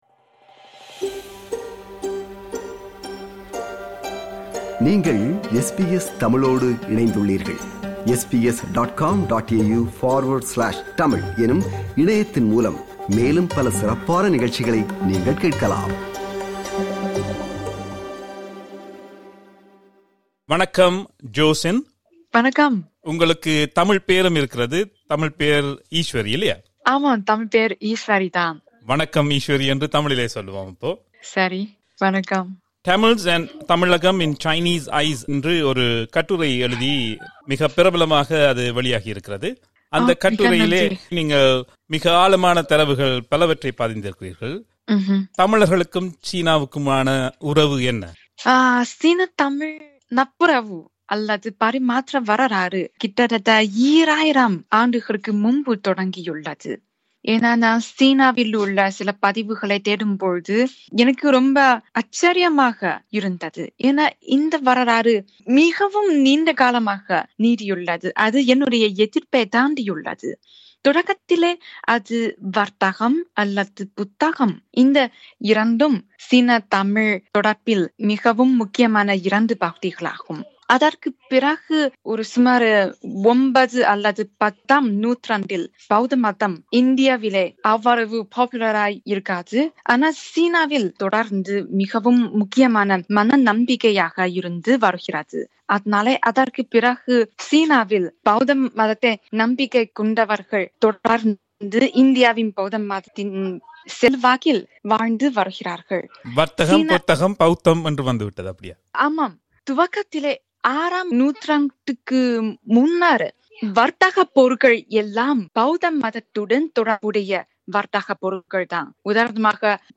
This interview was first broadcasted in 2019.